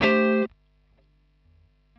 Am7.wav